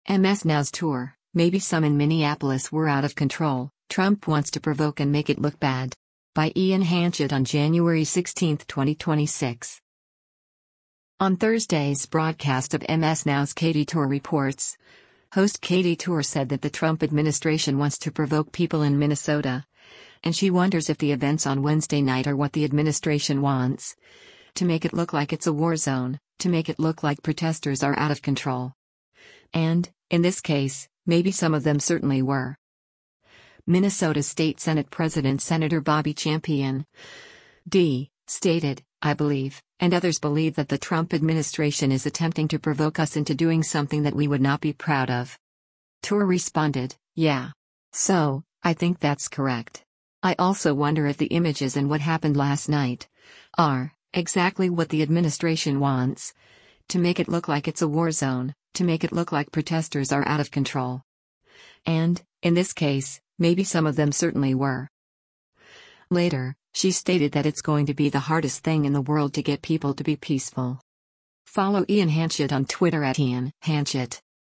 On Thursday’s broadcast of MS NOW’s “Katy Tur Reports,” host Katy Tur said that the Trump administration wants to provoke people in Minnesota, and she wonders if the events on Wednesday night are what the administration wants, “to make it look like it’s a war zone, to make it look like protesters are out of control.
Minnesota State Senate President Sen. Bobby Champion (D) stated, “I believe, and others believe that the Trump administration is attempting to provoke us into doing something that we would not be proud of.”